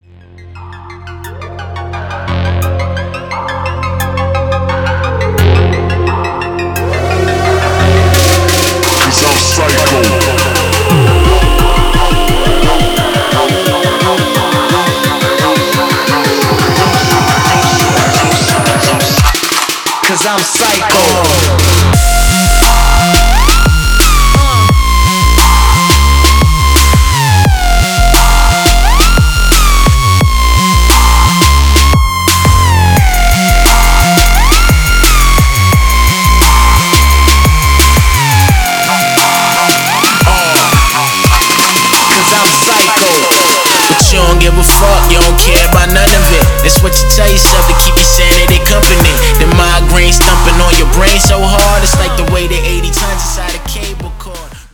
• Качество: 320, Stereo
громкие
жесткие
Electronic
EDM
нарастающие
Trap
качающие
Сирена
криповые
Криповое начало а потом полный отпад! 8)